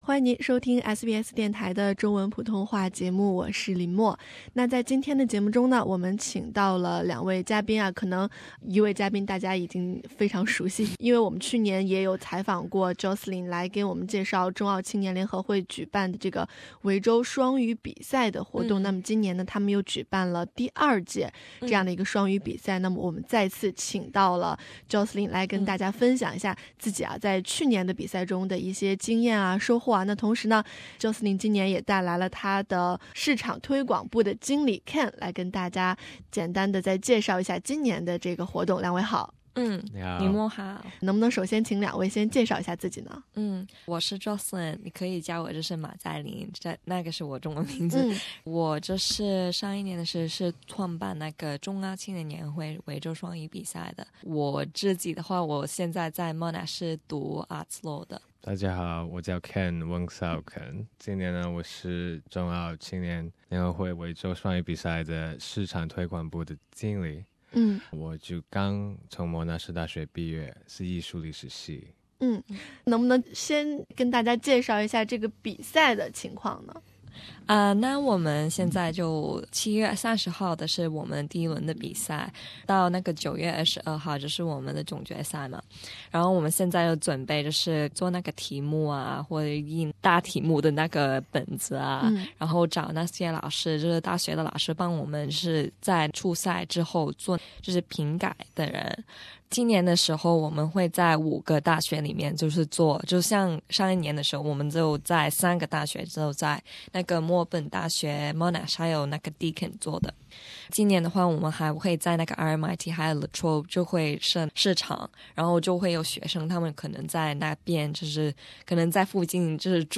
特别报道：2016ACYA双语比赛